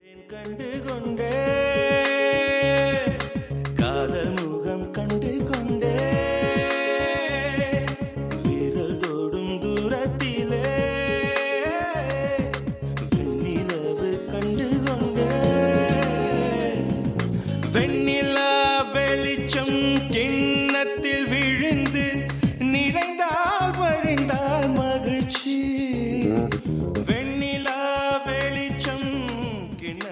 tamil ringtonelove ringtonemelody ringtoneromantic ringtone
best flute ringtone download